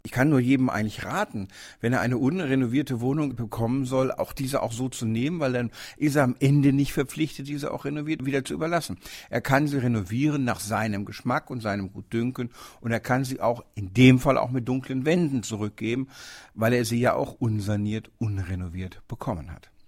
DAV, O-Töne / Radiobeiträge, Ratgeber, Recht, , , , , ,